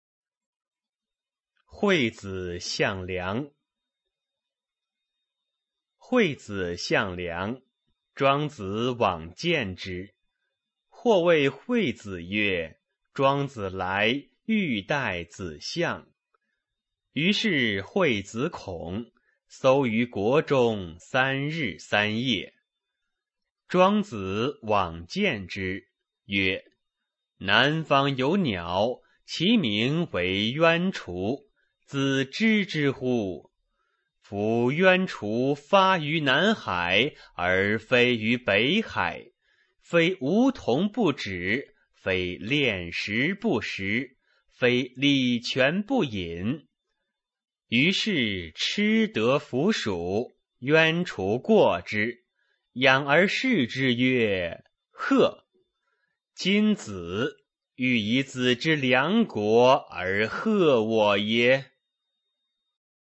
《惠子相梁》原文和译文（含mp3朗读）